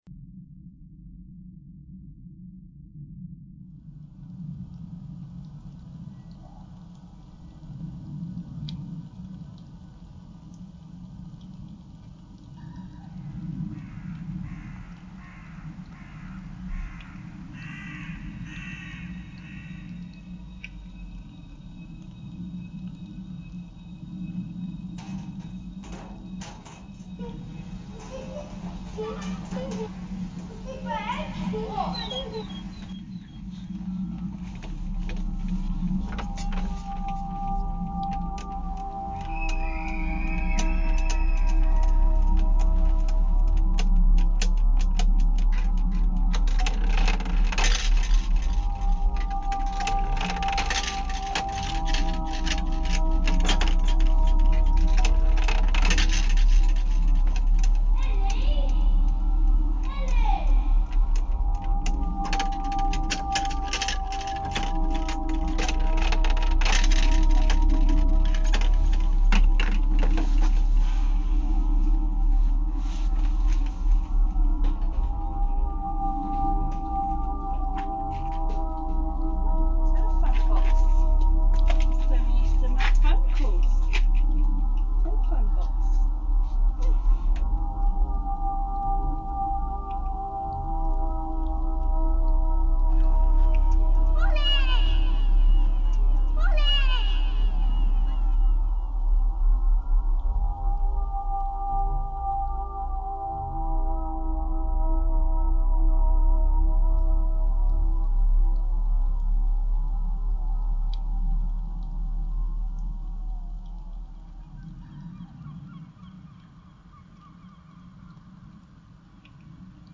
Field recording of the Kursaal estate in Southend-on-Sea. The Kursaal is one of the oldest buildings still standing in Southend.